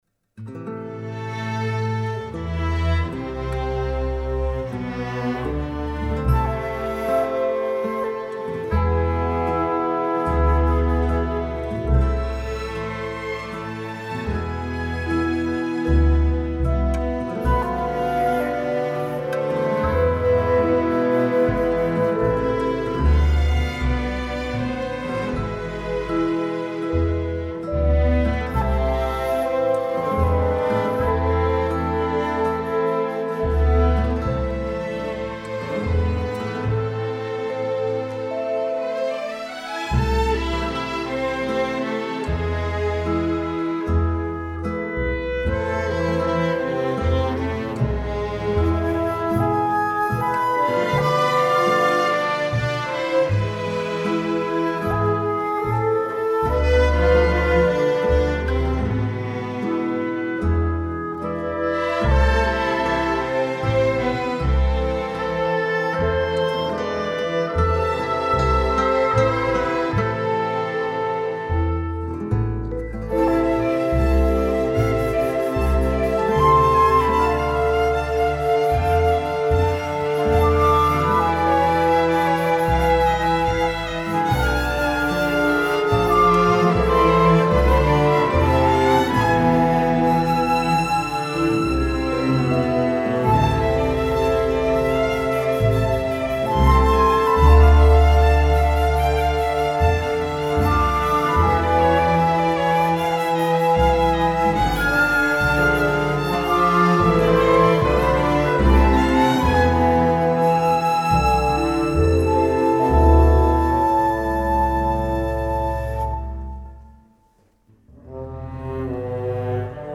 Επτά μουσικά θέματα ενορχηστρωμένα από τον συνθέτη
όπως και ελεύθερα  μουσικά θέματα κινηματογραφικής χροιάς.